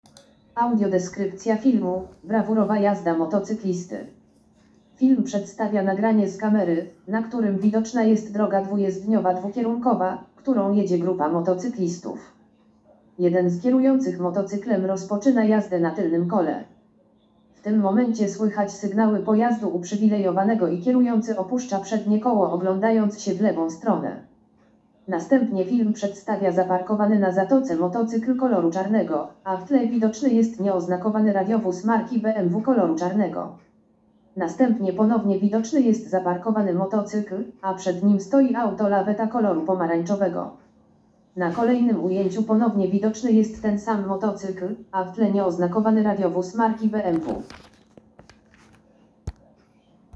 Nagranie audio Audiodeskrypcja_Filmu_brawurowa_jazda_motocyklisty.m4a